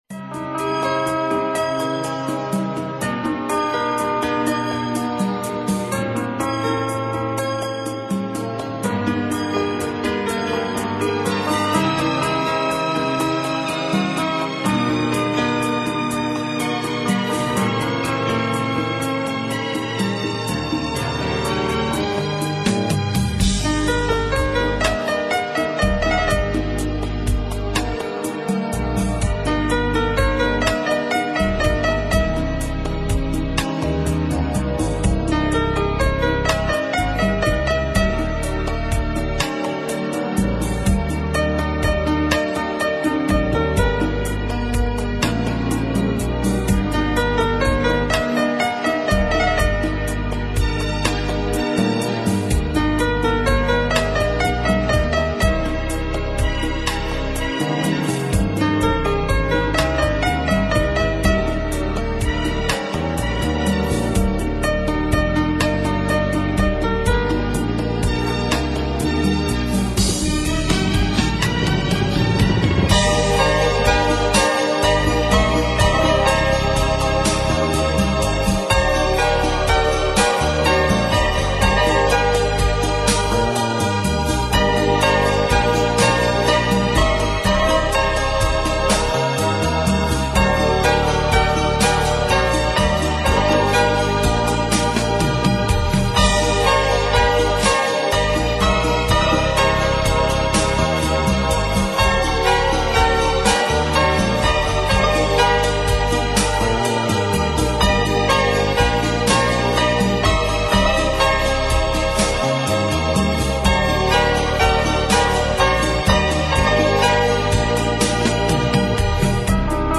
Krasivaya_duwevnaya_melodiya_nasimke__96x32.mp3